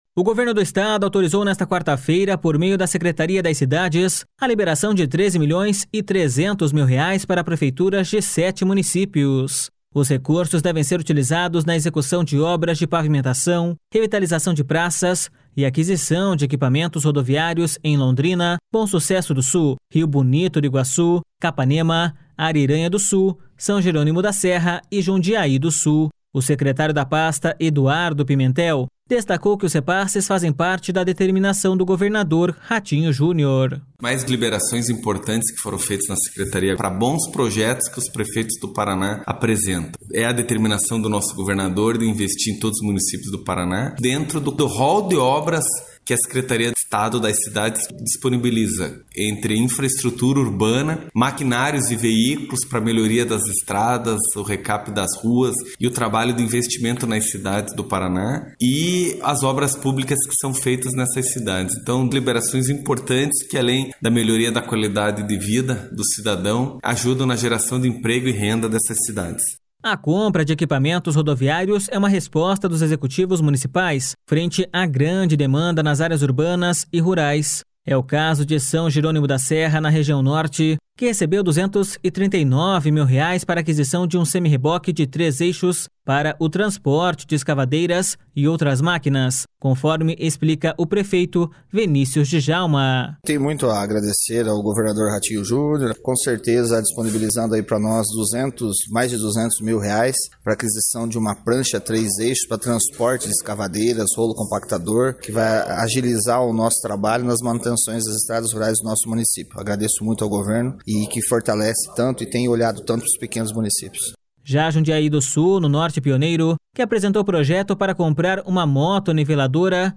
O secretário da pasta, Eduardo Pimentel, destacou que os repasses fazem parte da determinação do governador Ratinho Junior.// SONORA EDUARDO PIMENTEL.//
É o caso de São Jerônimo da Serra, na região Norte, que recebeu 239 mil reais para a aquisição de um semirreboque de três eixos para o transporte de escavadeiras e outras máquinas, conforme explica o prefeito Venícius Djalma.// SONORA VENÍCIUS DJALMA.//